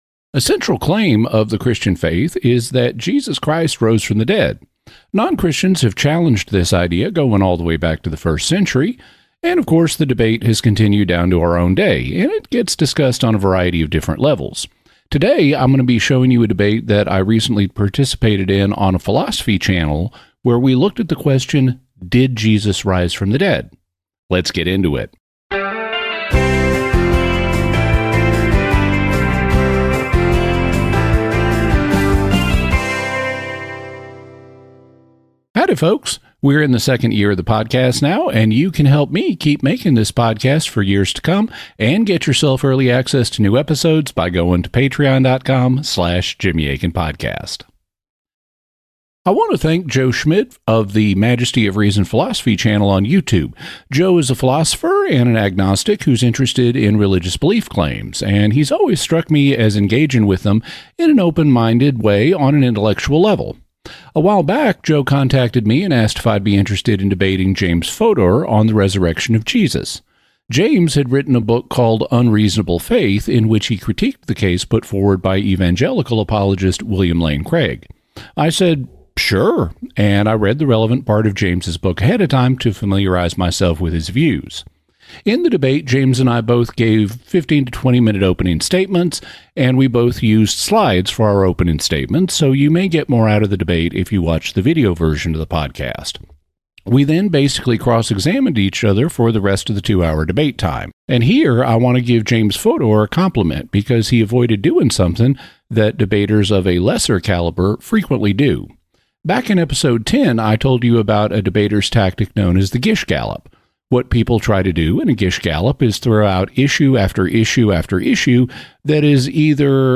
Did Jesus Rise from the Dead? DEBATE!